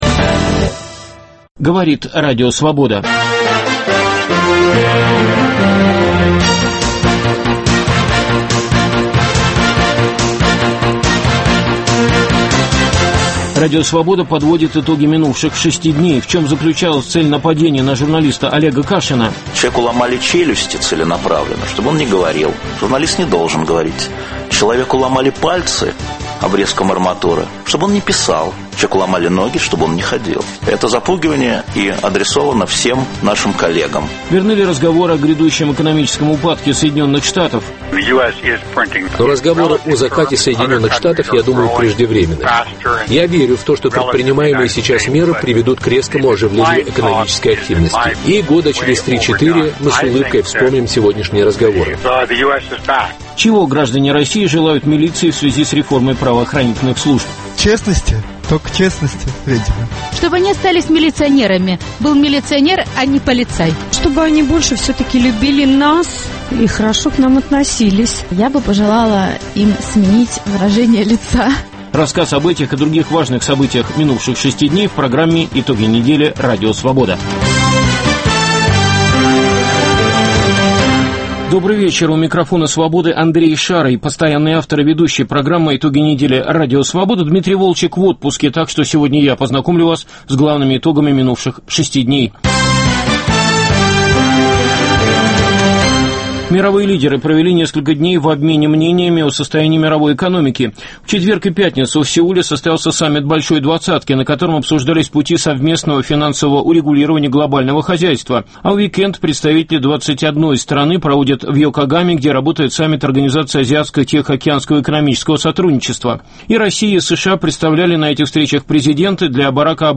По субботам в течение двух часов политические, экономические, социальные, культурные, светские итоги недели в России и в мире подводит в прямом эфире